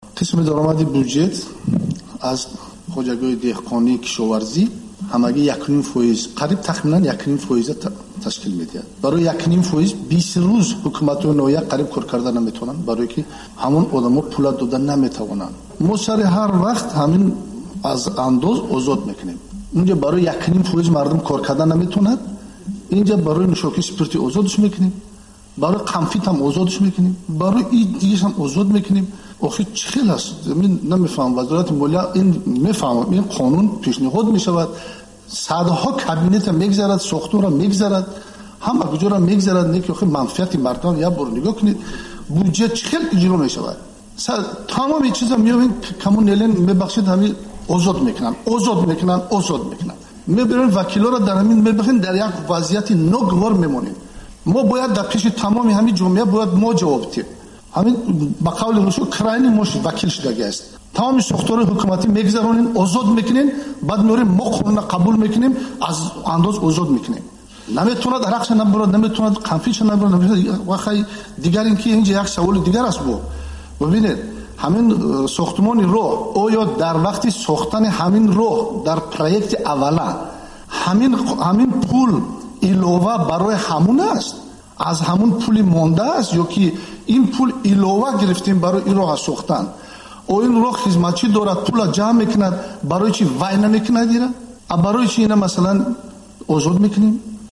Порае аз суҳбати Саидҷаъфар Усмонзода дар порлумон